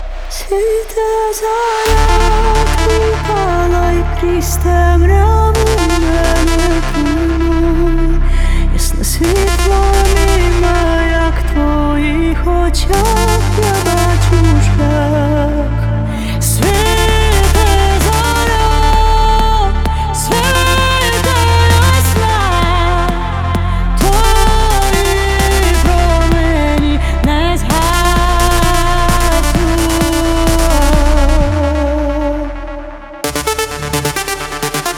2025-08-26 Жанр: Танцевальные Длительность